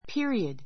pí(ə)riəd